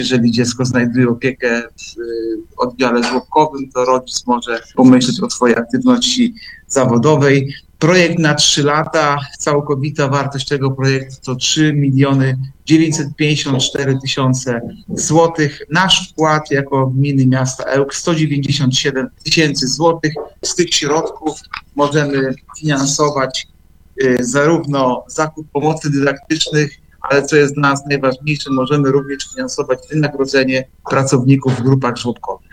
– Chcemy w ten sposób pomóc rodzicom maluchów w powrocie na rynek pracy – mówi Artur Urbański, zastępca prezydenta Ełku.